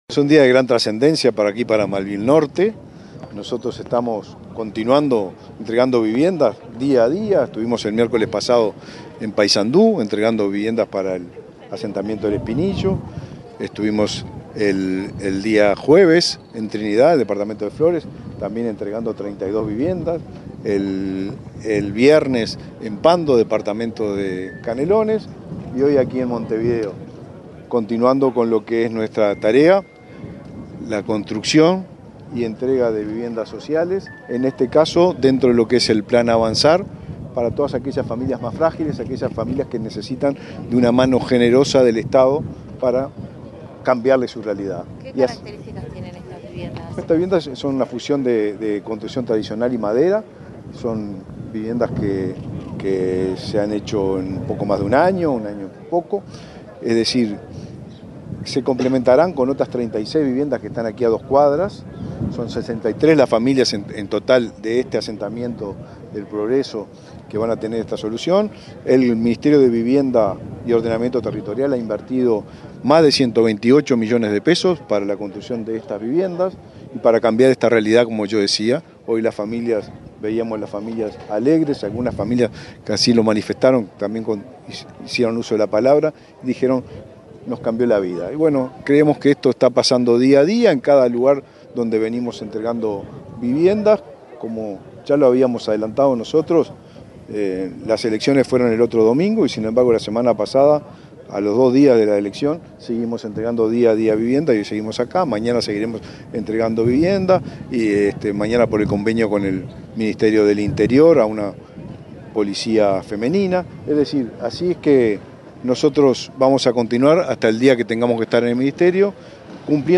Declaraciones del ministro de Vivienda, Raúl Lozano
Luego, dialogó con la prensa.